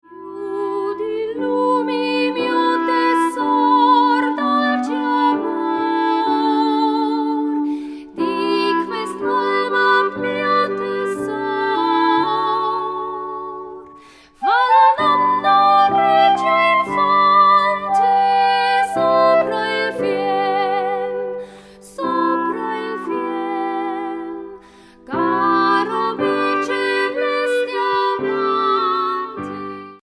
for soprano/mezzo-soprano, clarinet and piano
Description:Classical; vocal music; chamber music; Christmas
Instrumentation:Soprano or mezzo-soprano, clarinet, piano